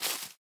Minecraft Version Minecraft Version 25w18a Latest Release | Latest Snapshot 25w18a / assets / minecraft / sounds / block / azalea_leaves / step1.ogg Compare With Compare With Latest Release | Latest Snapshot